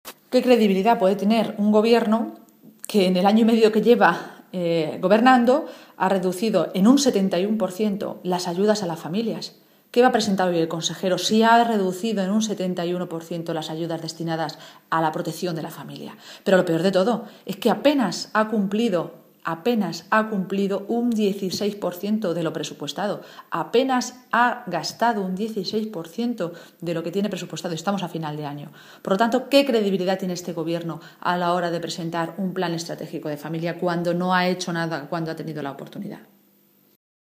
Cristina Maestre, portavoz de la Ejecutiva Regional del PSOE de Castilla-La Mancha
Cortes de audio de la rueda de prensa